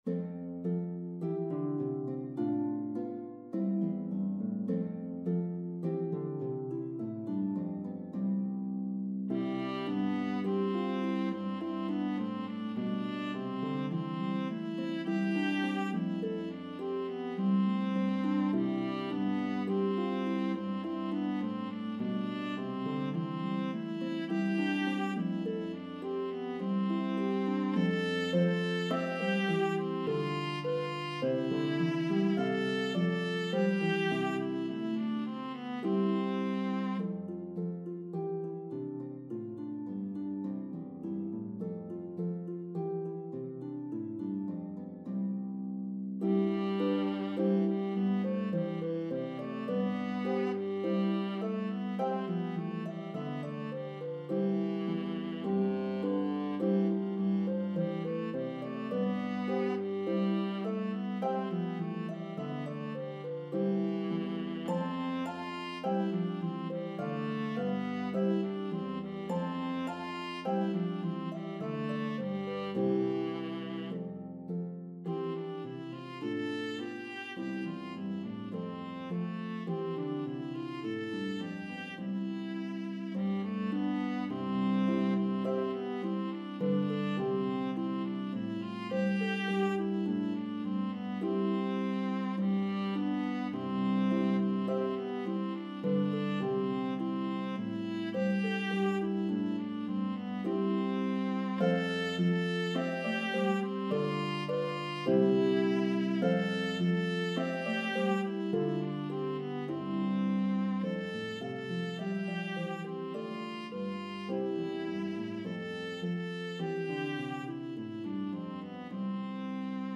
This arrangement has 4 verses separated by interludes.